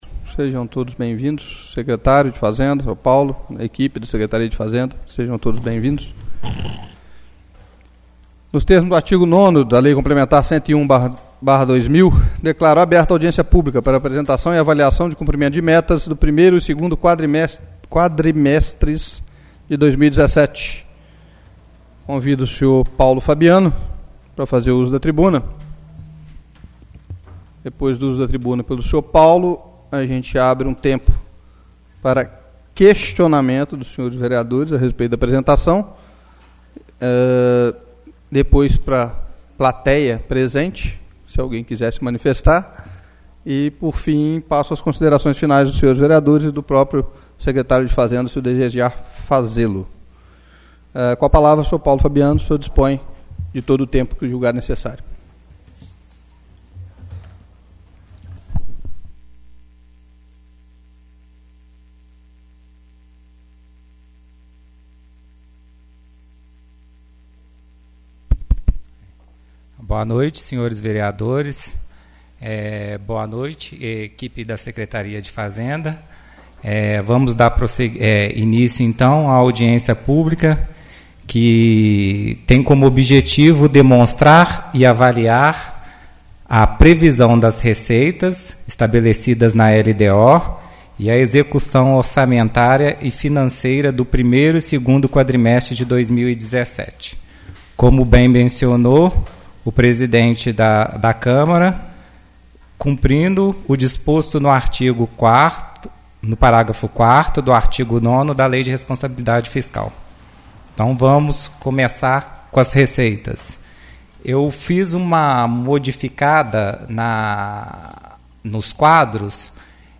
Audiência Pública Quadrimestre 24/10/2017 — Câmara Municipal